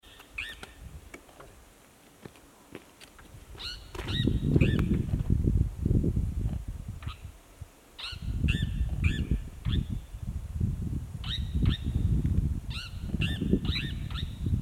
Crested Gallito (Rhinocrypta lanceolata)
Life Stage: Adult
Location or protected area: Parque Nacional Sierra de las Quijadas
Condition: Wild
Certainty: Photographed, Recorded vocal
Gallito-copeton.mp3